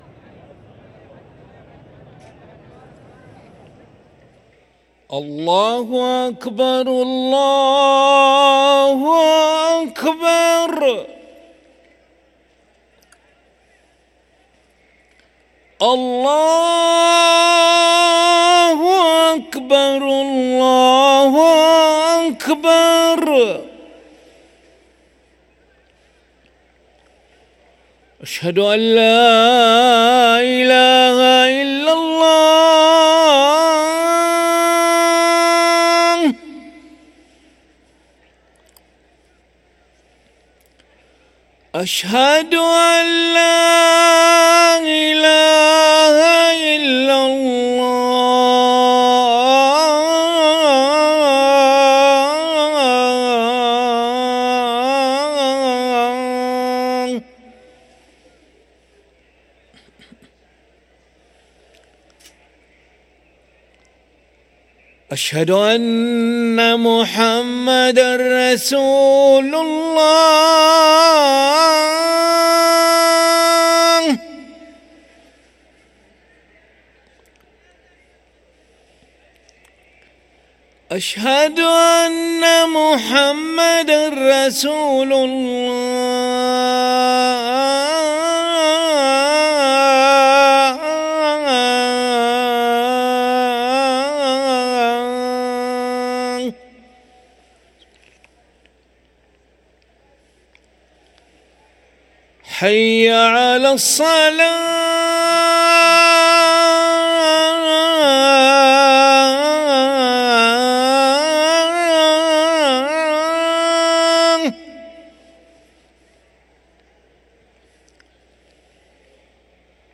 أذان العشاء للمؤذن علي ملا الخميس 29 صفر 1445هـ > ١٤٤٥ 🕋 > ركن الأذان 🕋 > المزيد - تلاوات الحرمين